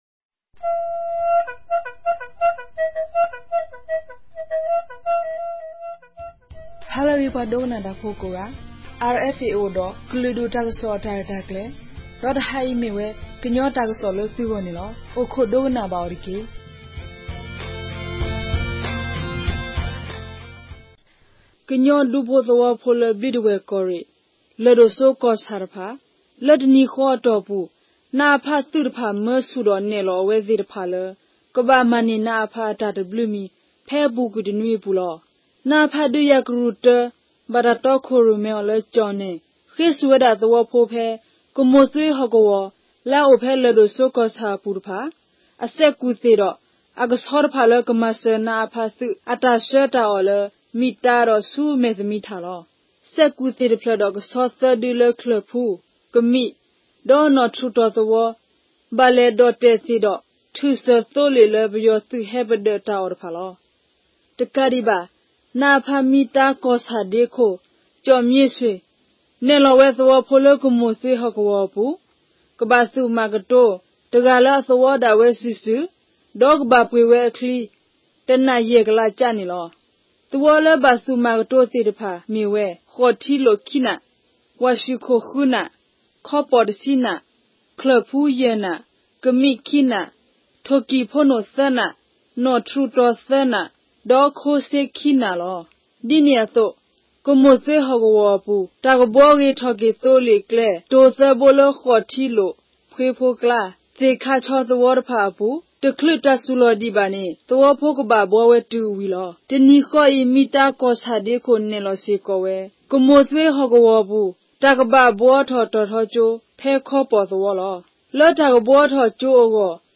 ကရင်ဘာသာ အသံလြင့်အစီအစဉ်မဵား